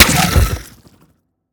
biter-death-4.ogg